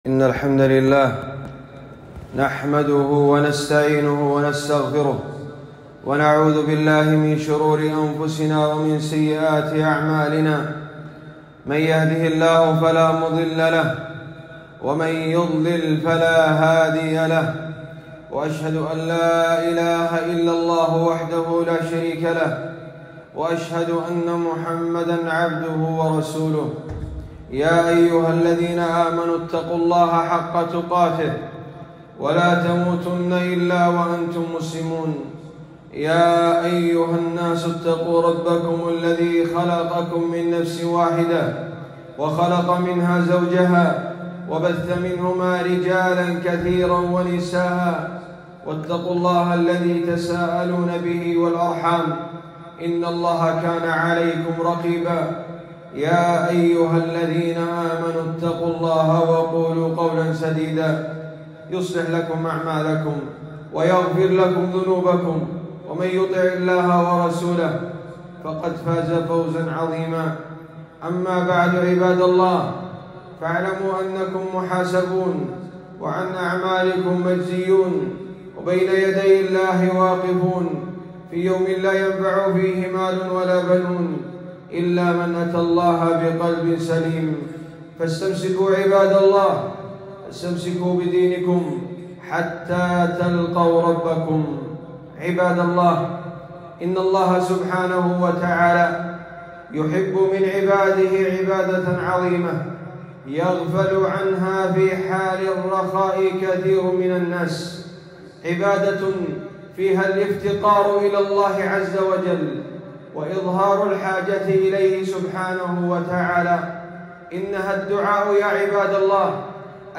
خطبة - ( وقال ربكم أدعوني أستجب لك م) - دروس الكويت